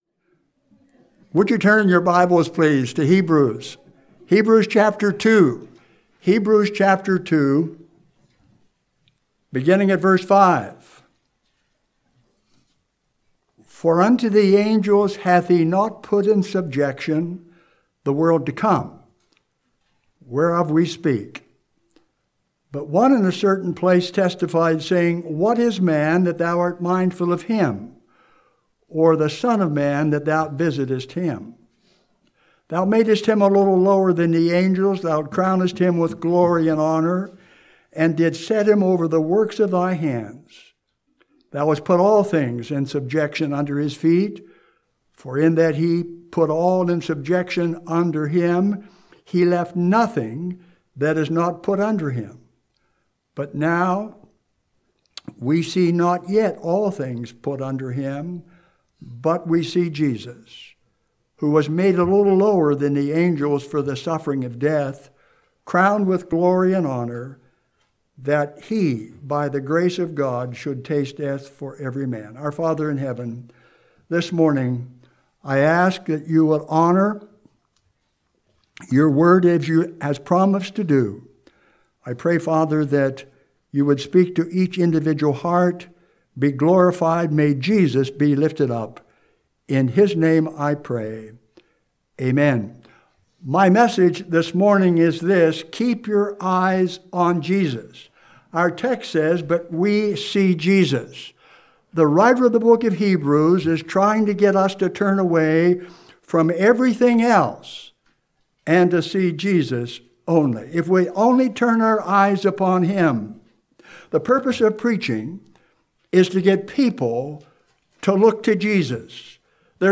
Sunday Morning Sermon